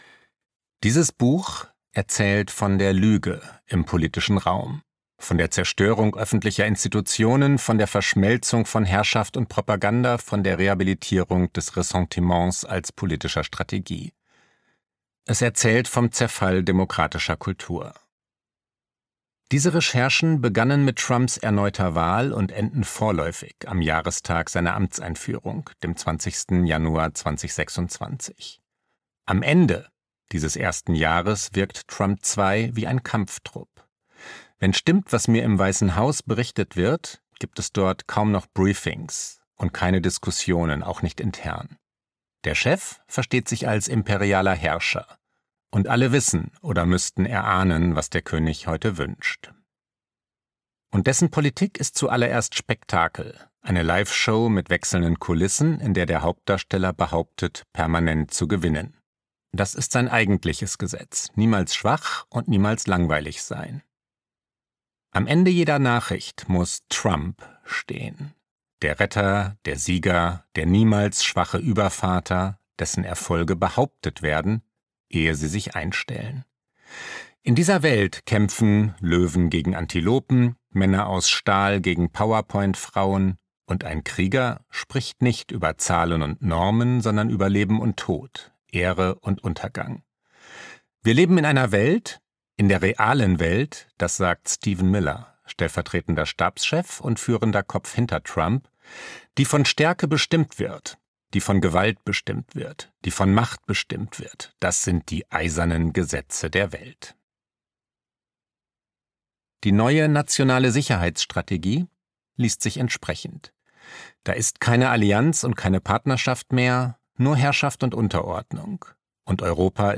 Gekürzt Autorisierte, d.h. von Autor:innen und / oder Verlagen freigegebene, bearbeitete Fassung.
Der amerikanische Albtraum Gelesen von: Klaus Brinkbäumer